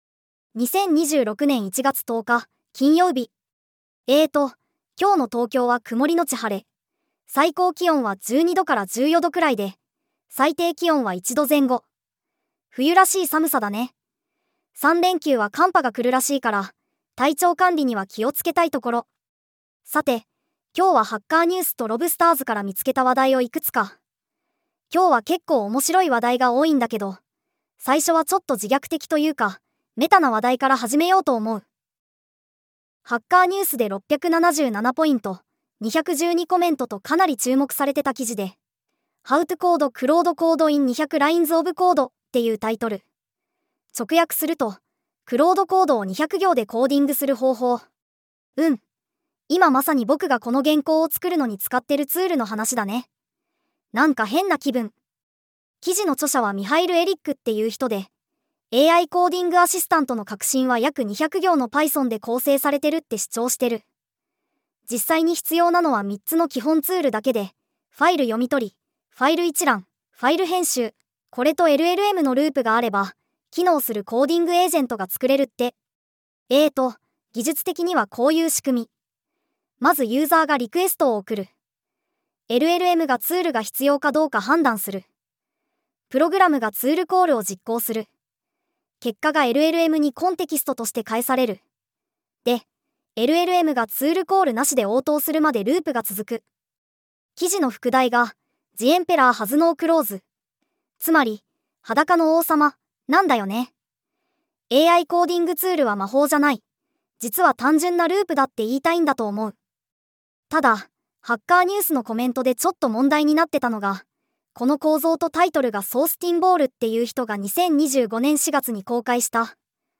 テトさんに技術系ポッドキャストを読んでもらうだけ